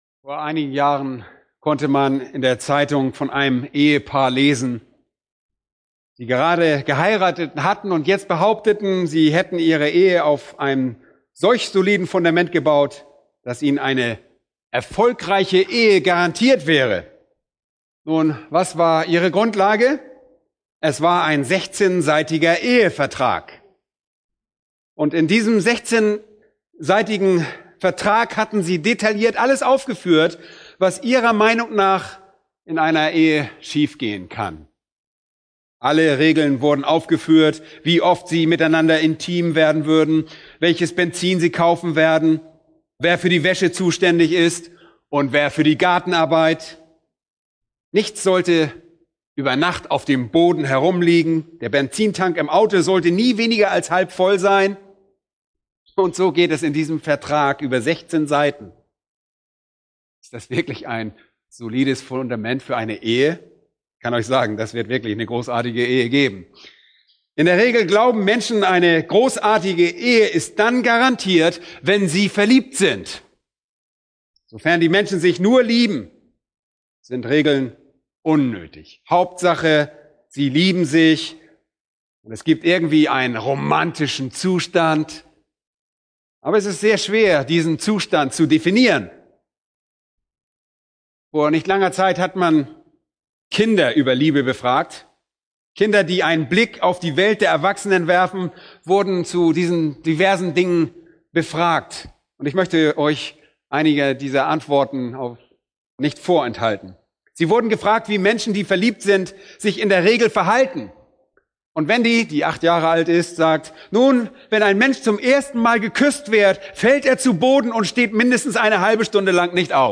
Eine predigt aus der serie "Markus."